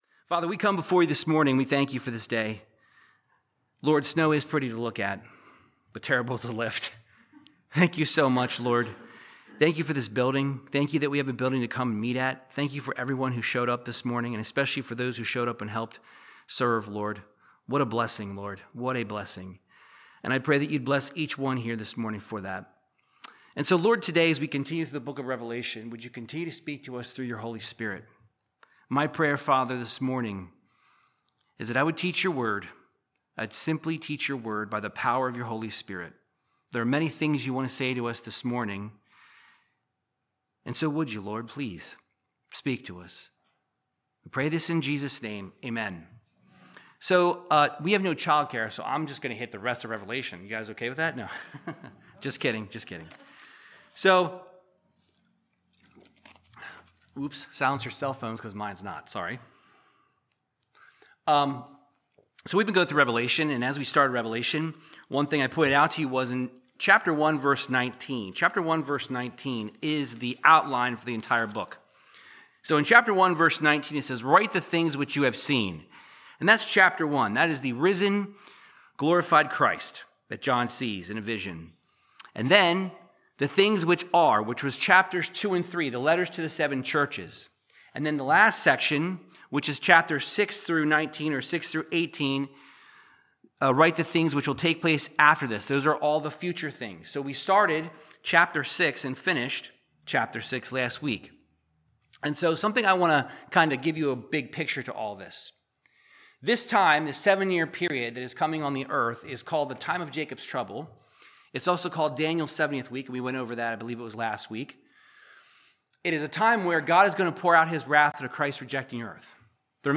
Verse by verse Bible teaching through the book of Revelation chapter 7